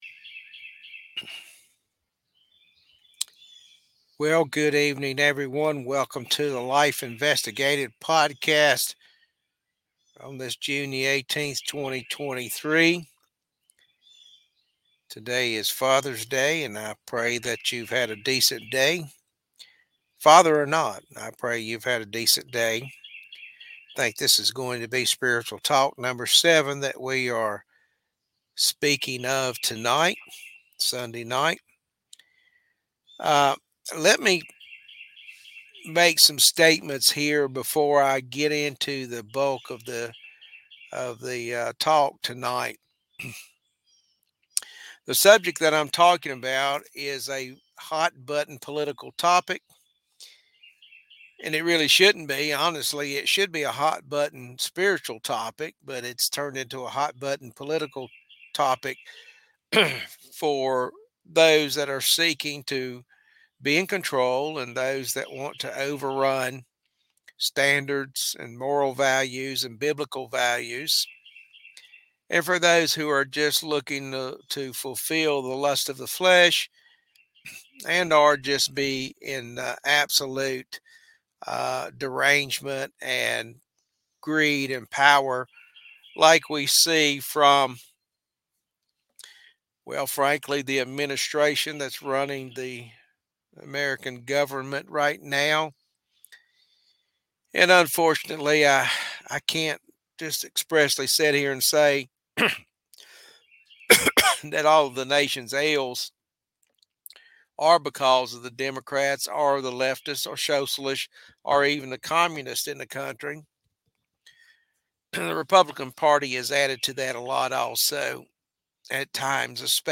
Spiritual Talk